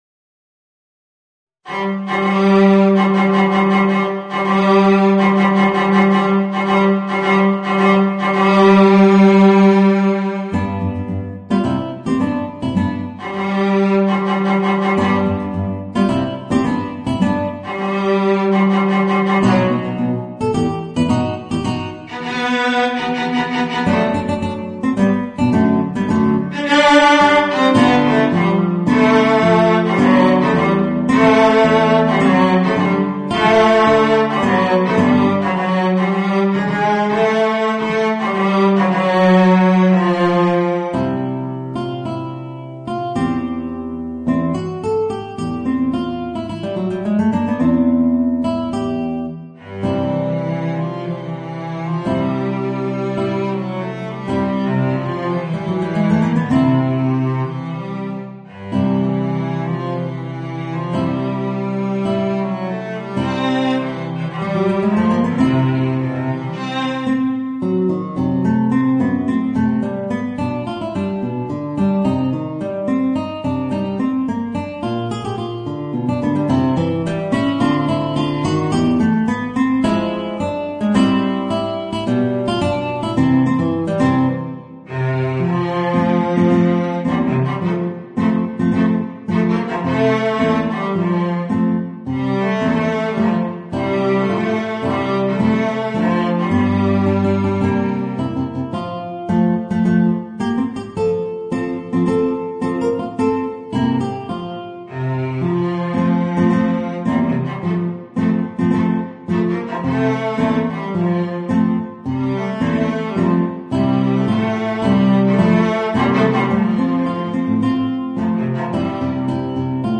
Voicing: Guitar and Violoncello